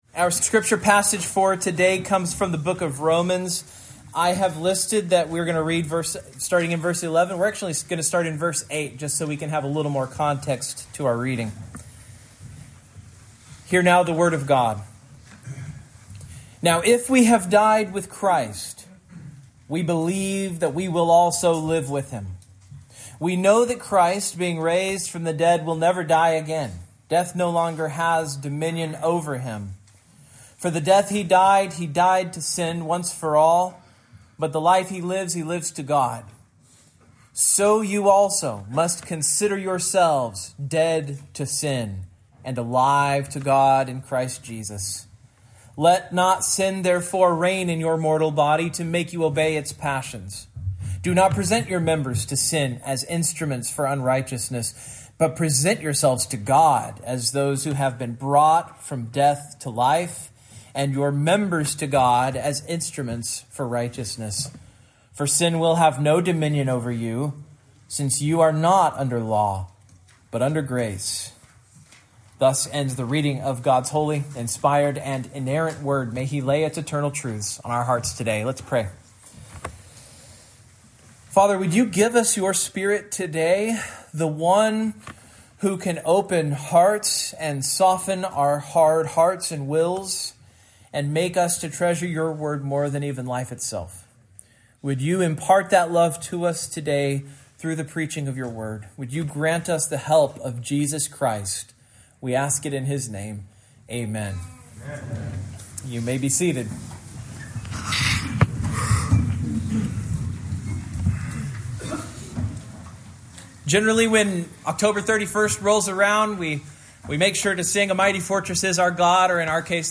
Sermon
Service Type: Morning